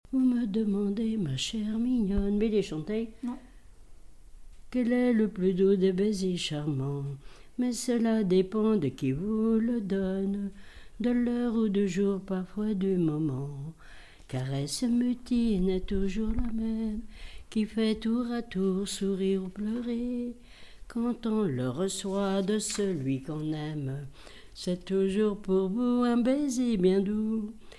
circonstance : fiançaille, noce
témoignage et chansons
Pièce musicale inédite